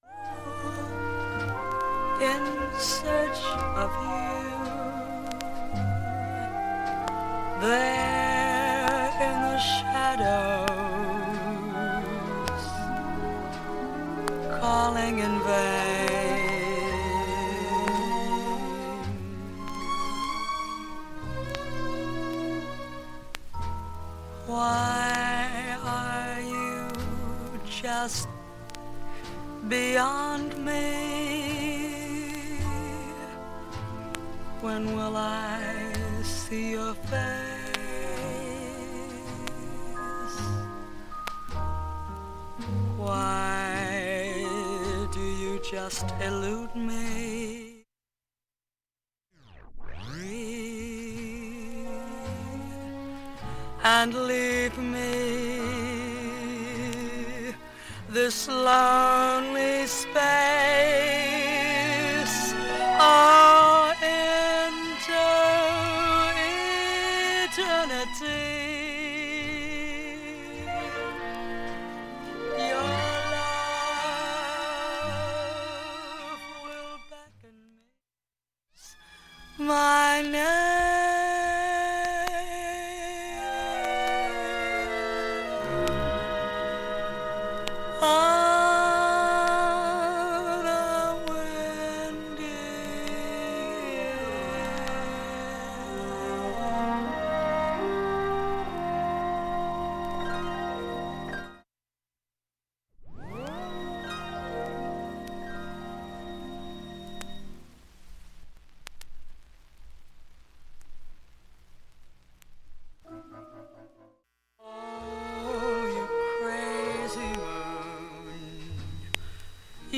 盤面きれいです音質良好全曲試聴済み。
５回までのかすかなプツが５箇所
３回までのかすかなプツが１３箇所
単発のかすかなプツが１６箇所